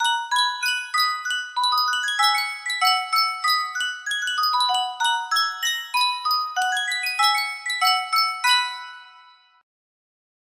Sankyo Miniature Music Box - Love is Here to Stay FNV music box melody
Full range 60